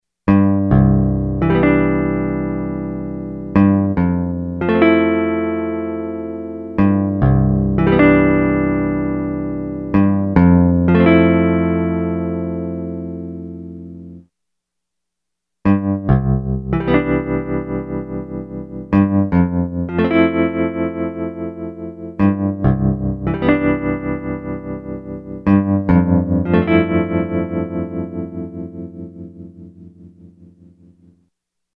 I used a Roland JV-2080 (expanded) with its internal effects turned off.
The dry sound is played first, followed by the processed version.
20 PAN I tweaked this preset a little bit - to make it sound like a tremolo effect a la David Lynch/Angelo Badalamenti.
20 PAN.mp3